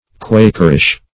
\Quak"er*ish\